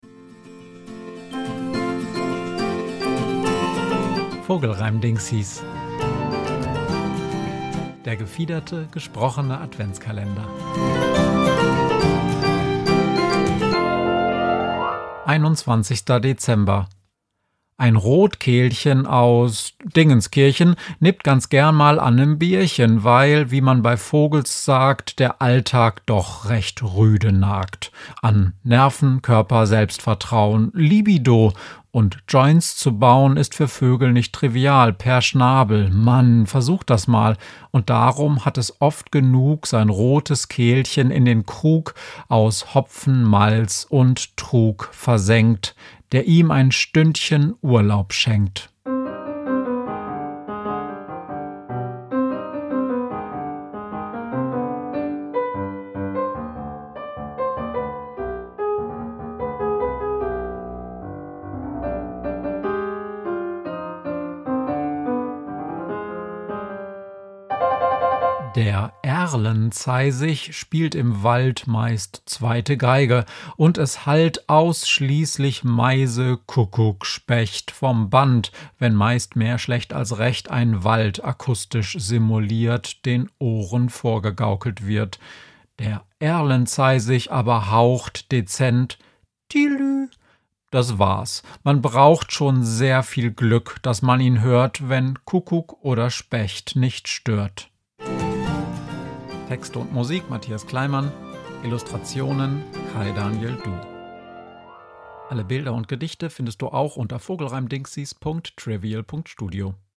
Vogelreimdingsis ist der gefiederte, gesprochene Adventskalender